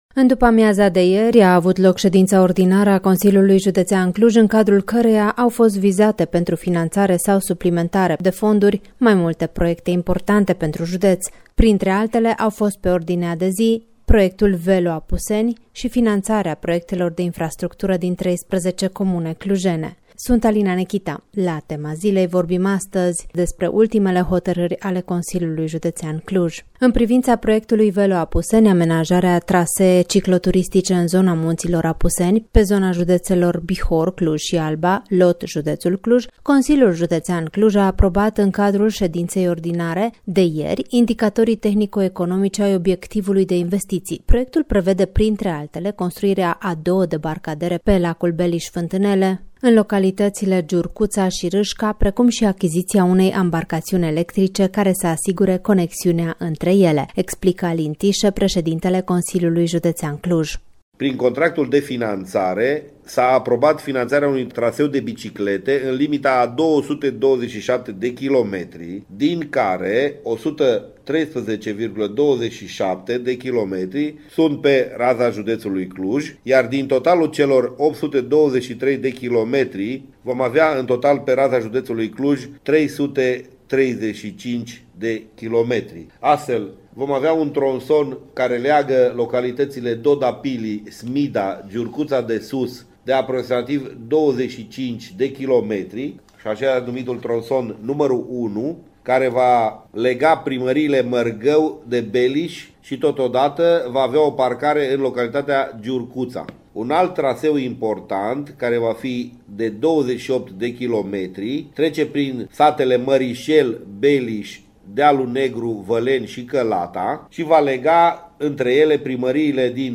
Alin Tișe, președintele Consiliului Județean Cluj:
sedinta-ordinara-Tise.mp3